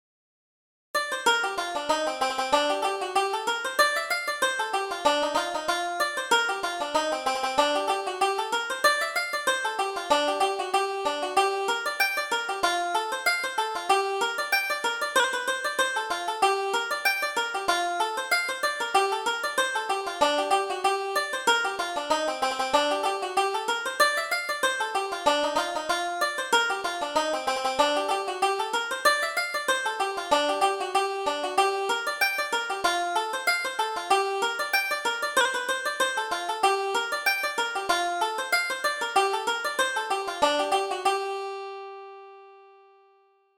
Reel: The Girl Who Broke My Heart